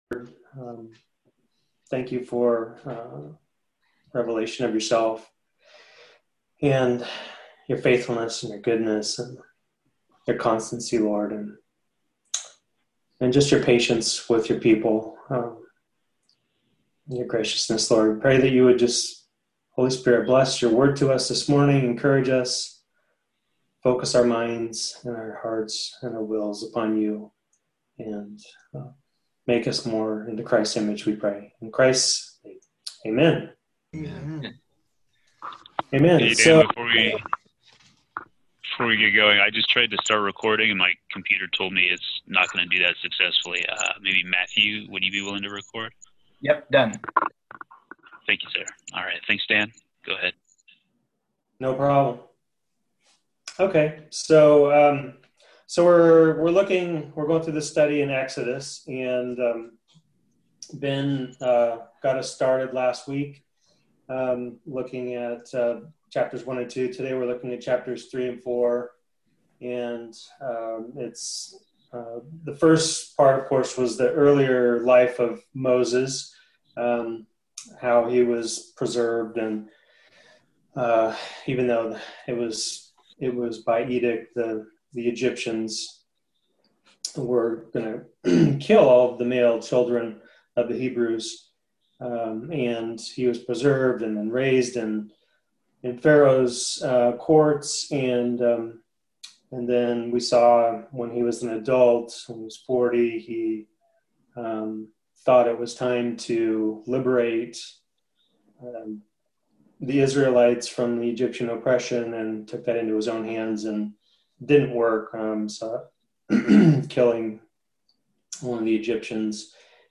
The Birth of the Covenant Nation Passage: Exodus 3-4 Service Type: Sunday School « Lesson 1